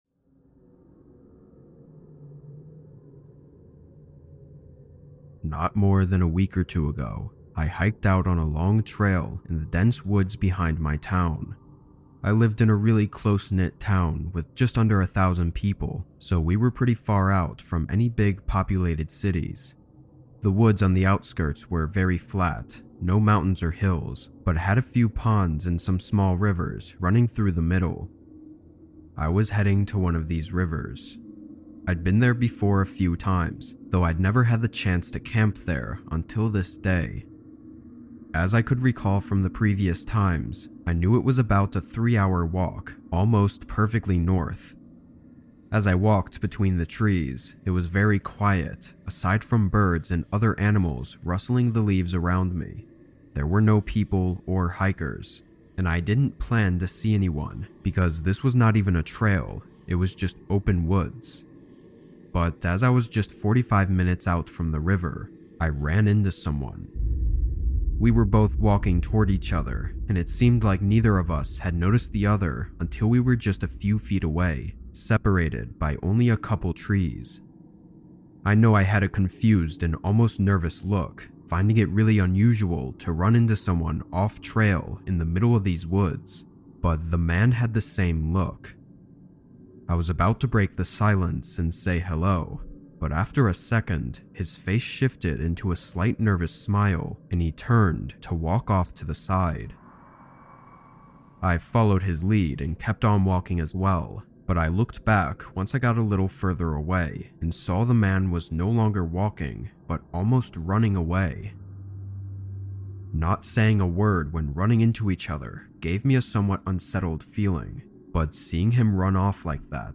Rainy Night Alone Horror Stories That Will Freeze Your Soul | With Rain Sounds
All advertisements are placed exclusively at the beginning of each episode, ensuring complete immersion in our horror stories without interruptions.